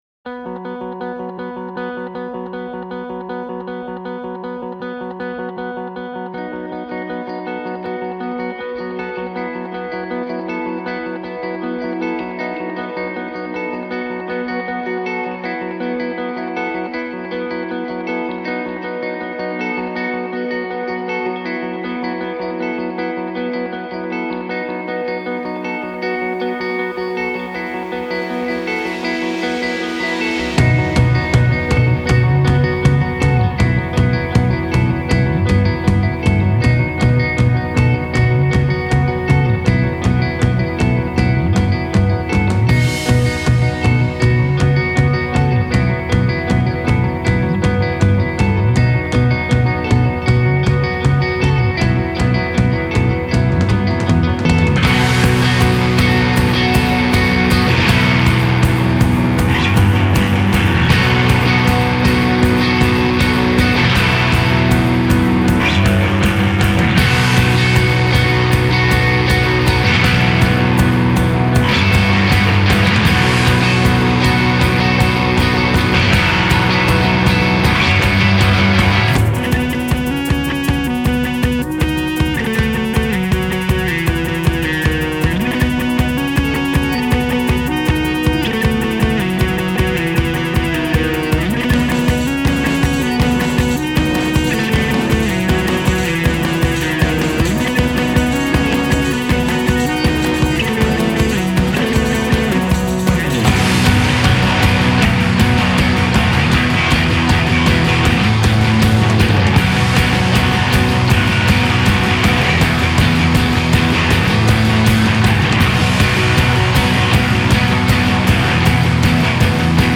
Genres: Art rockindie rockpost-hardcore,alternative rock